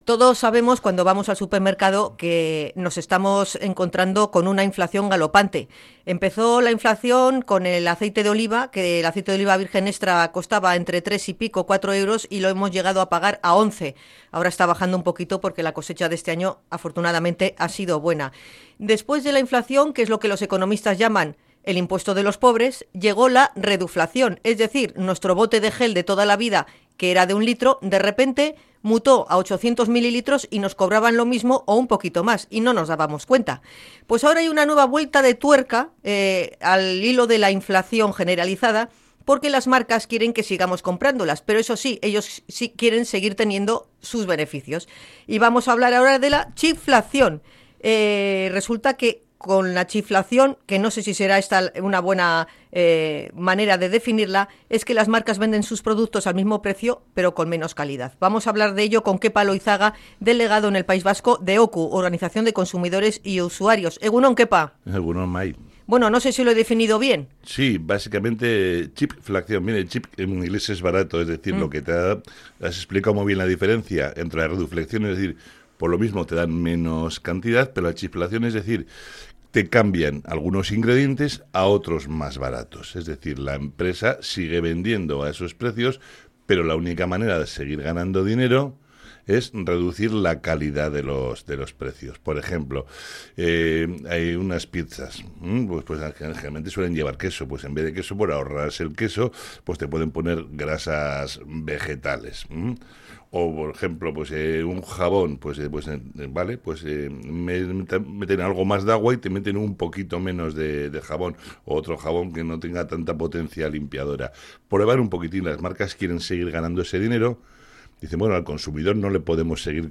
INT.-CHEAPFLACION.mp3